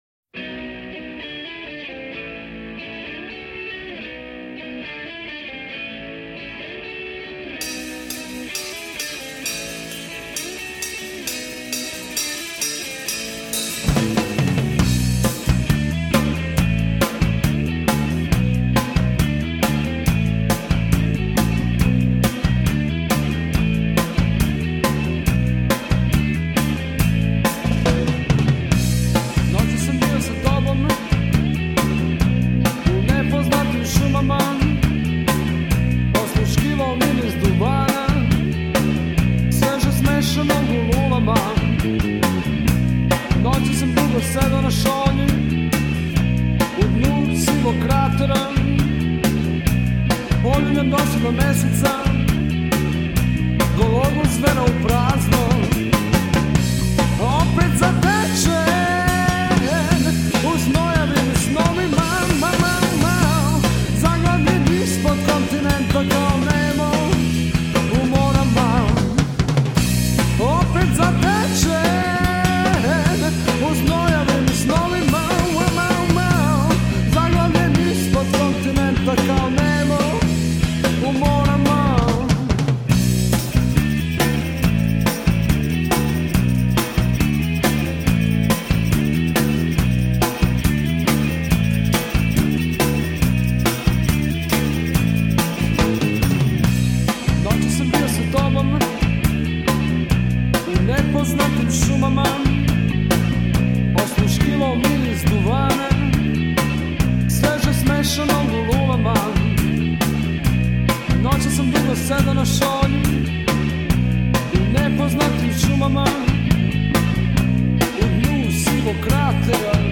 od ritma i bluza, do pank-roka.
Vokal
gitara
bas gitara
bubanj